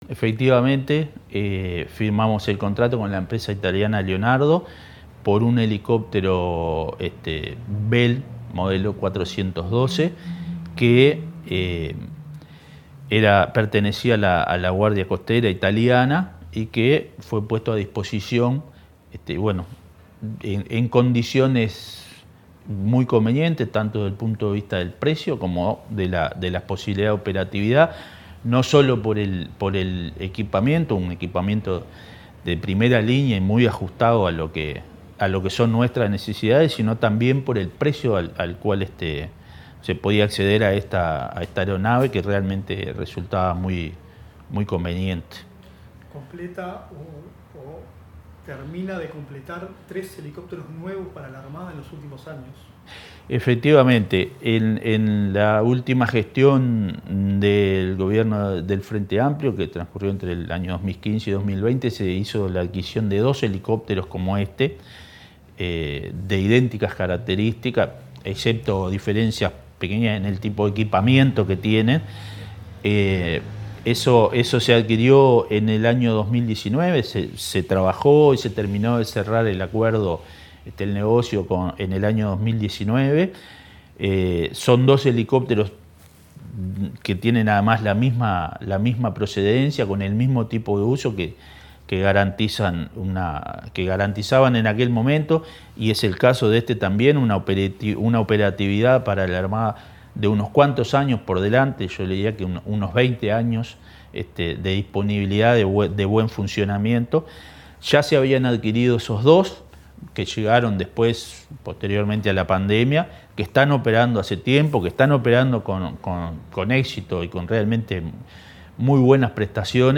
Declaraciones del subsecretario de Defensa Nacional, Joel Rodríguez